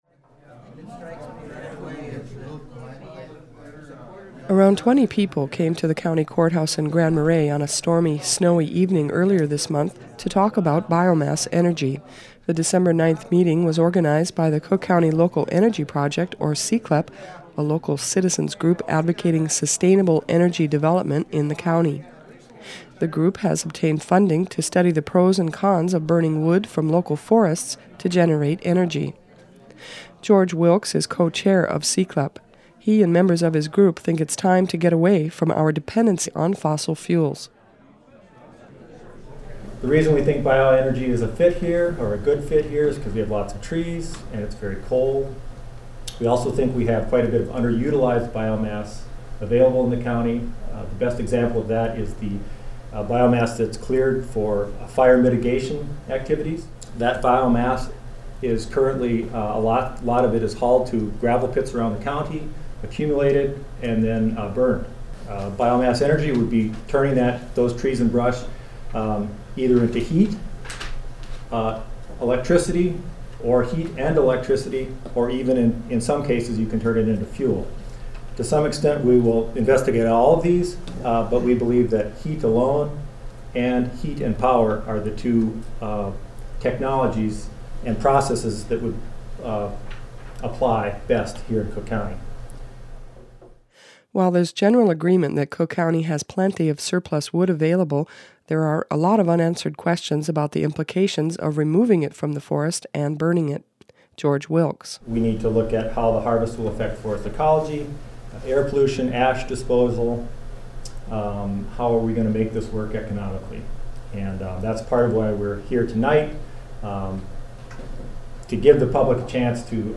Around 20 people came to the County Courthouse in Grand Marais on a stormy, snowy evening earlier this month to talk about biomass energy.